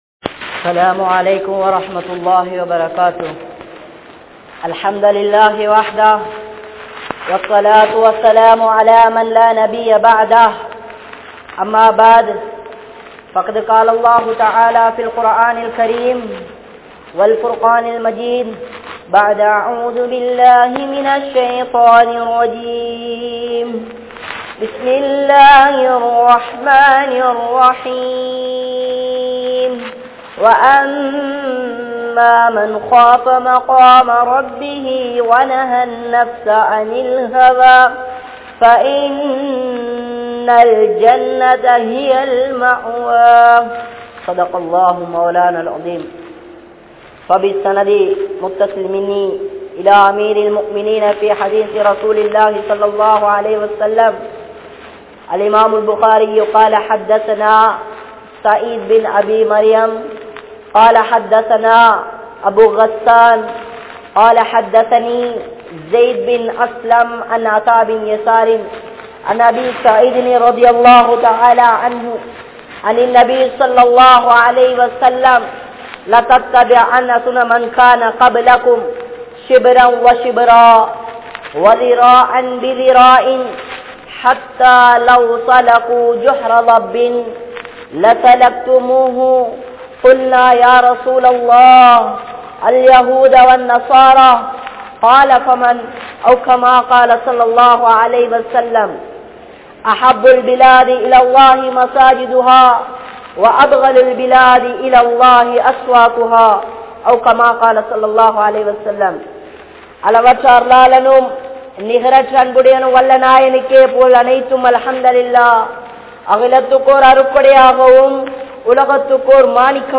Alinthu Poahum Ulaha Vaalkai (அழிந்து போகும் உலக வாழ்க்கை) | Audio Bayans | All Ceylon Muslim Youth Community | Addalaichenai
Nawalapitiya, Balanthota, Badhuriya Jumua Masjidh